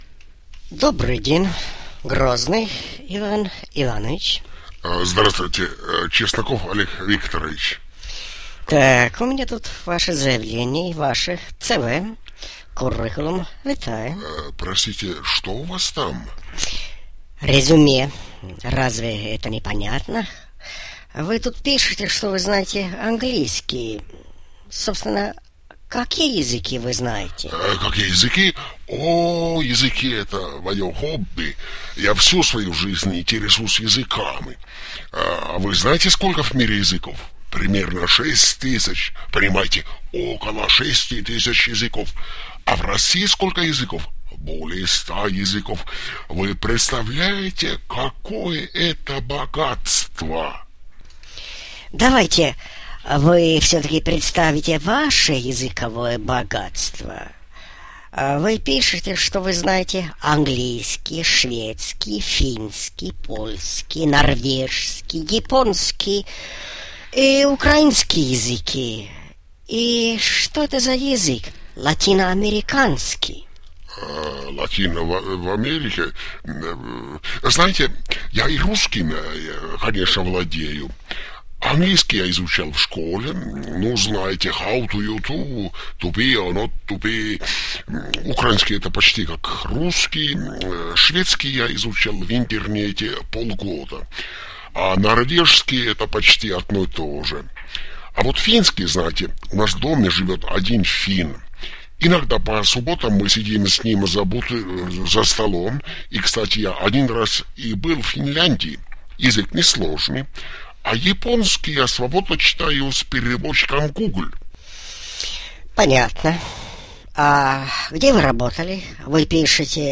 1. Интервью с первым кандидатом.